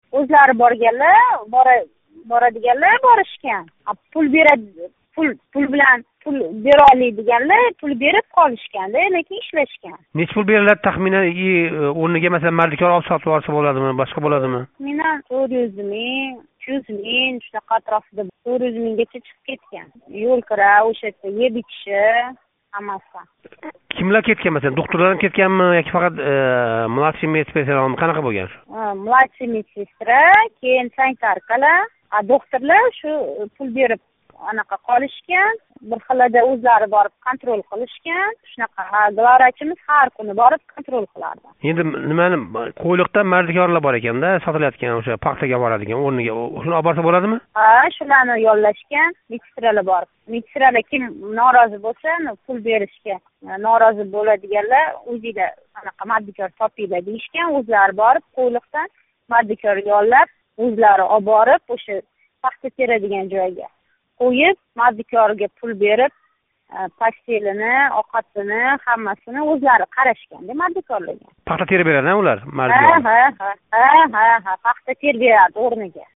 Ўзбекистон ҳукумати бу йилги пахта йиғим-терим мавсумини "самарали" ўтказишга қаттиқ аҳд қилган кўринади. Озодлик мухбири Сирдарё пахта далаларида жавлон ураётган академия илмий ходимлари билан гаплашди.